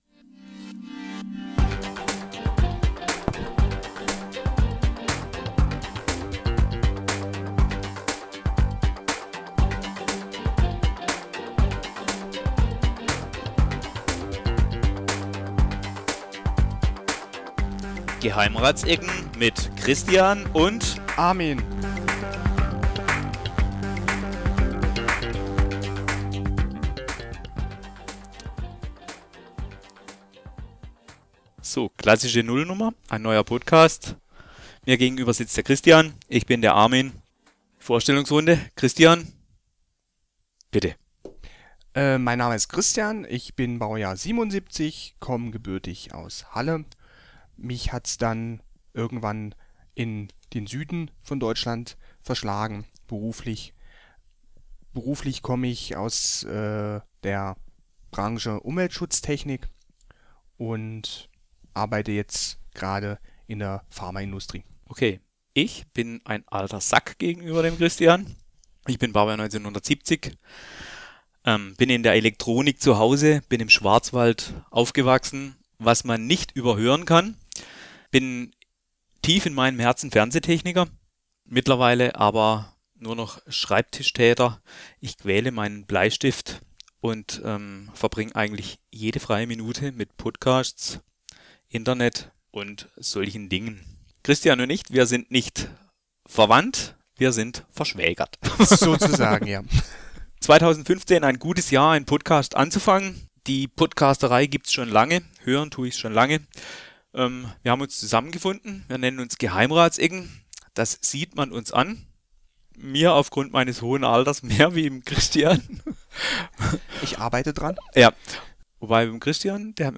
Ein Podcast, produziert im Südwesten von einem “nativ-Badner” und einem zugereisten Hallenser…